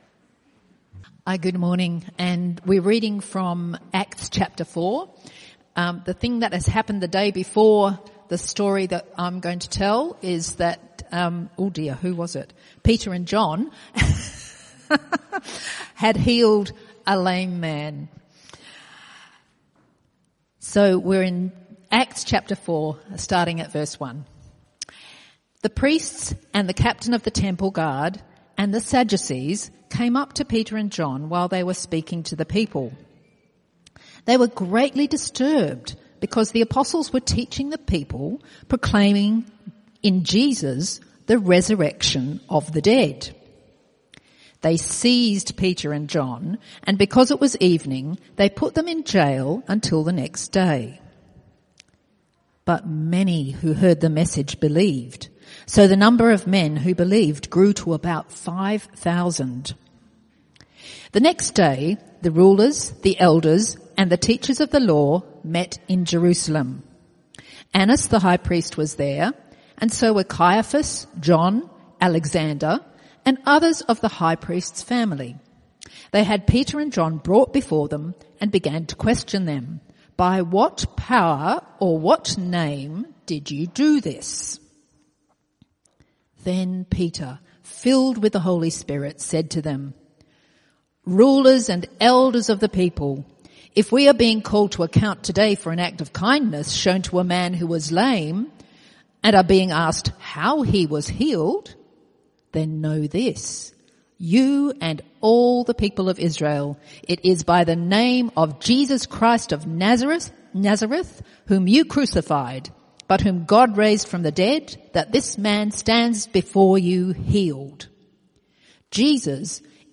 CBC Service